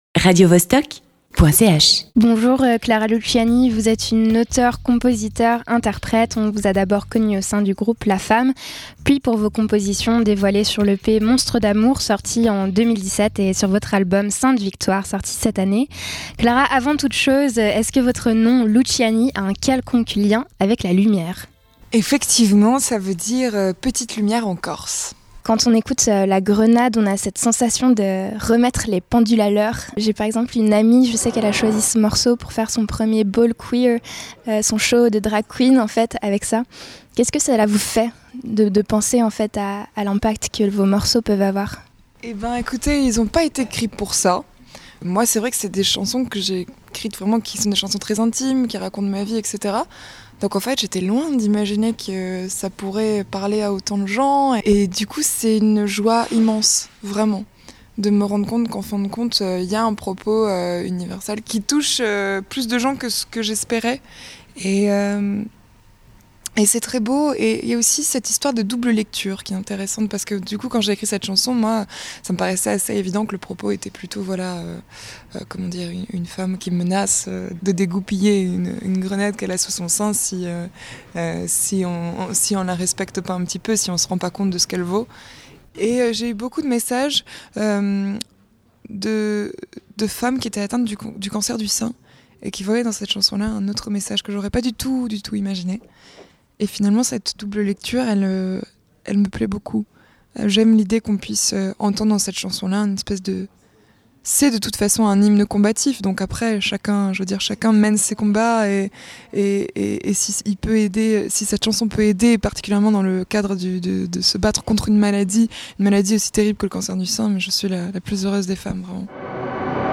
Itw_Clara-Luciani.mp3